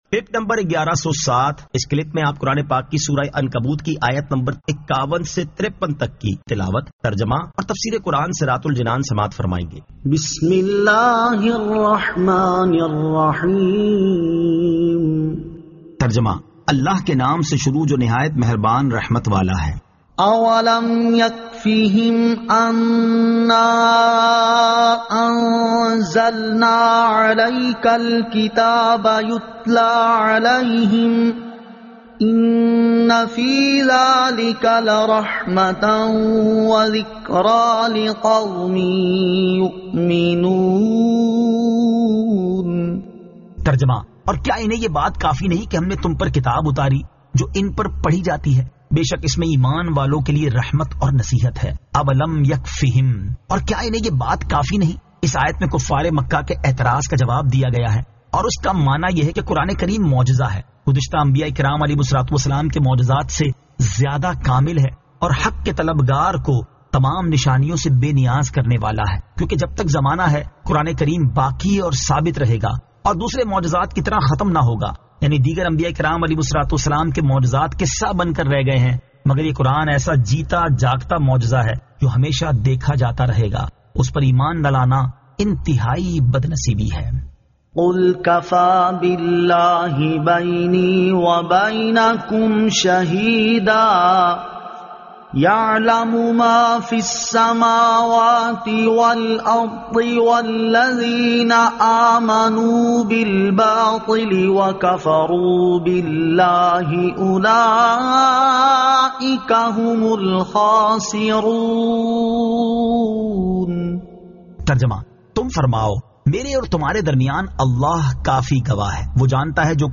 Surah Al-Ankabut 51 To 53 Tilawat , Tarjama , Tafseer